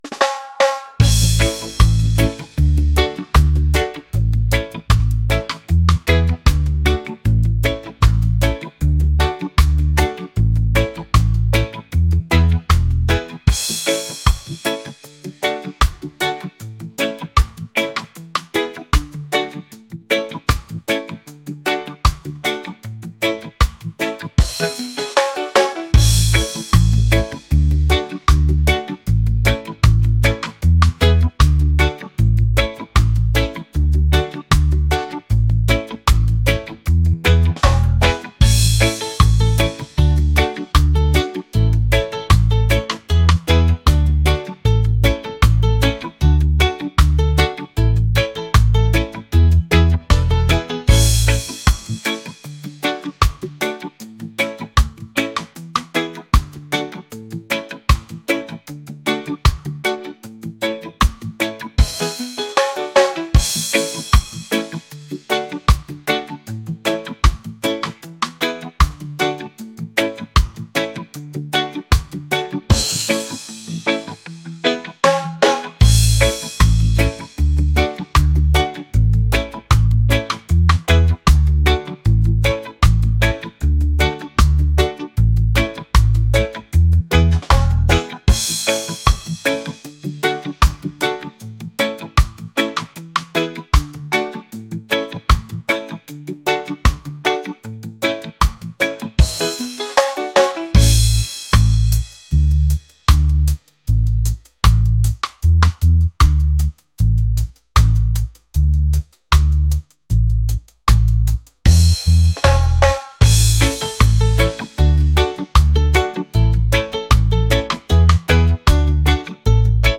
reggae | funk | lounge